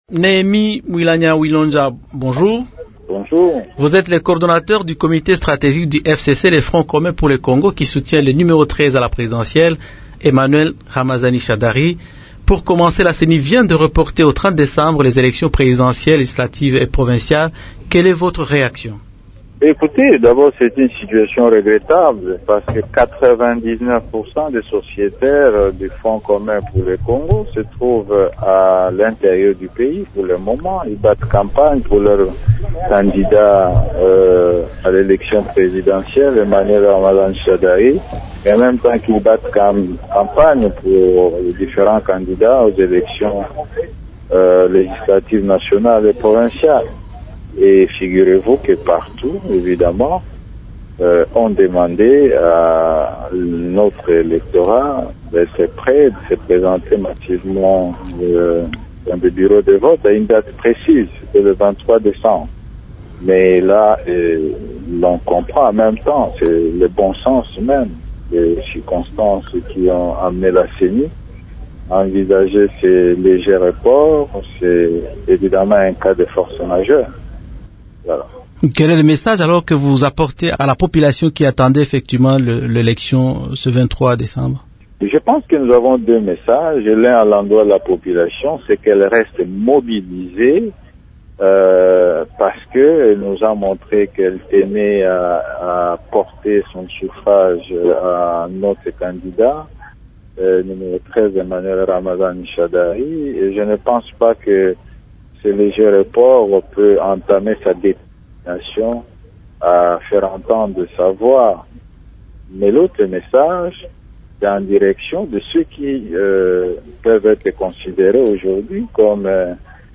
Néhémie Mwilanya parle du programme d’Emmanuel Ramazani Shadary.